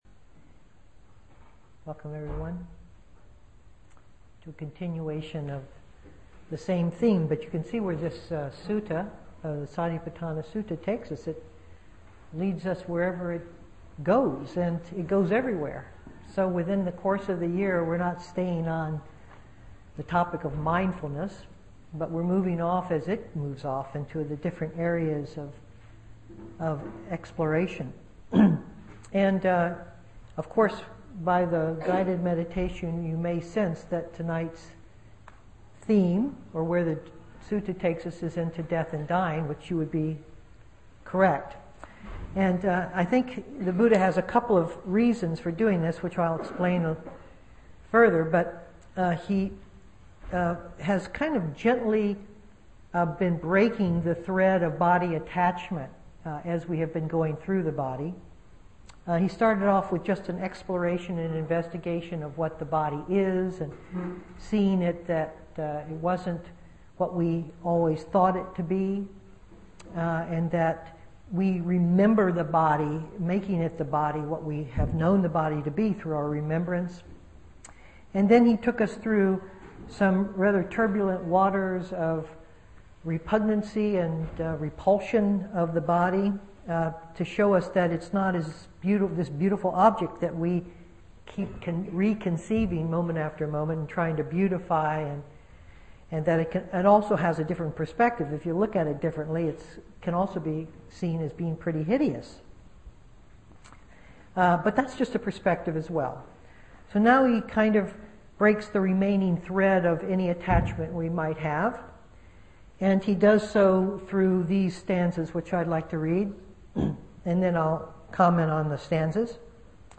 2010-07-06 Venue: Seattle Insight Meditation Center